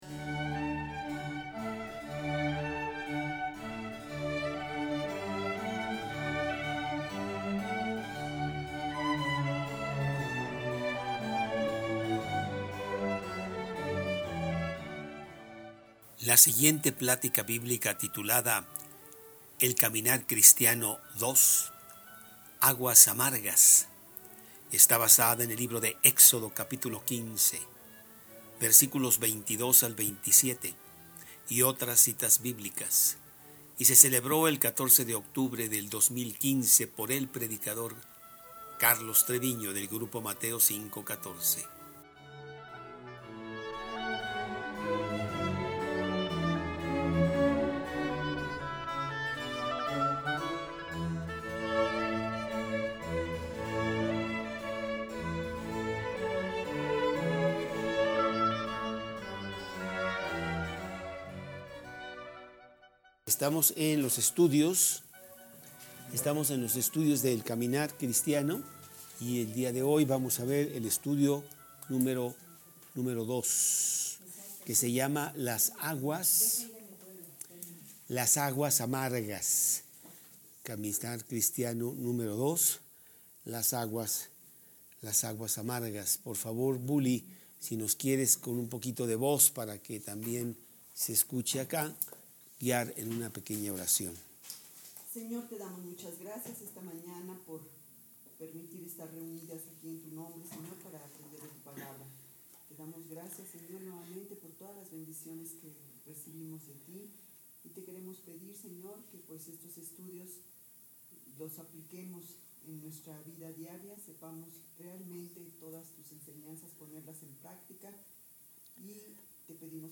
2015 El Caminar Cristiano 2 Aguas Amargas Preacher